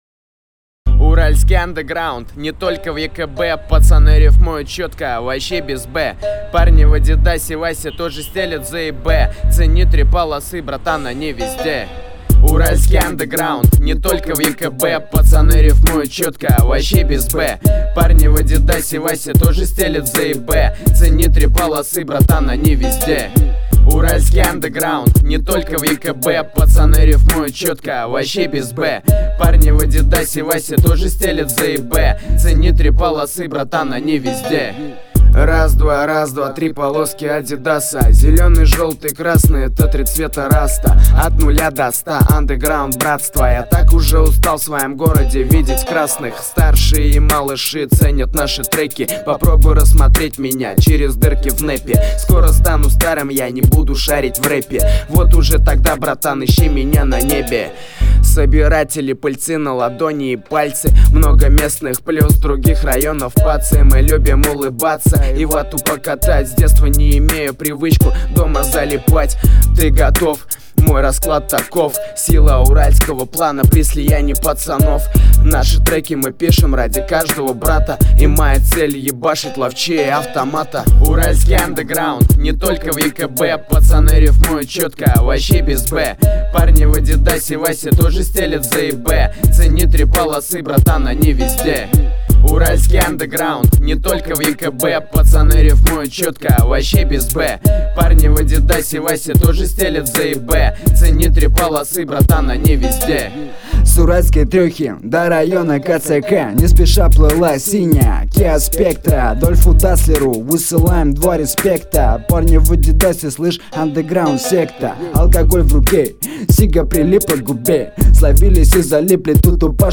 Категория: Рэп, хип - хоп